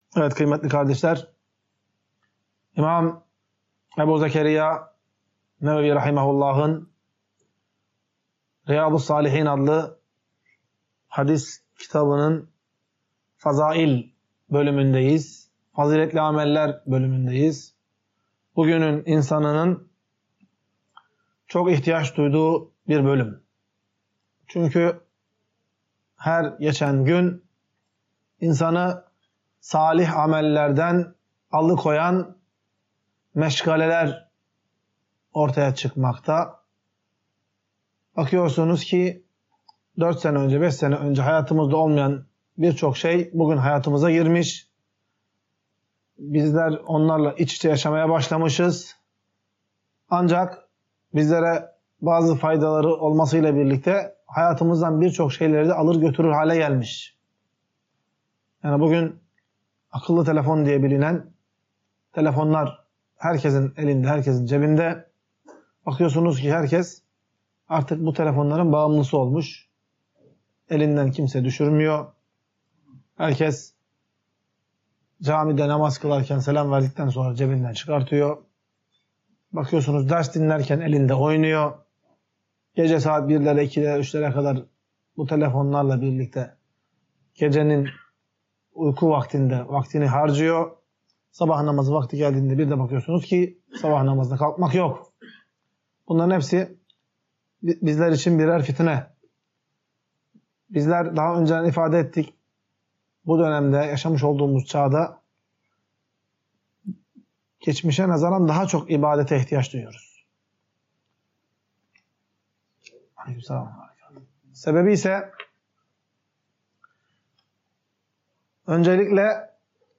Ders - 21. BÖLÜM | İKİNDİ NAMAZININ SÜNNETİ